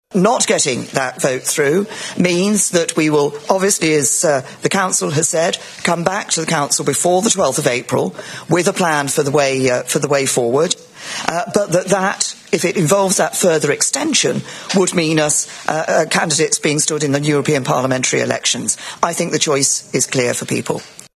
Speaking after the meeting with EU leaders, British Prime Minister Theresa May says the House of Commons now has to back the deal………..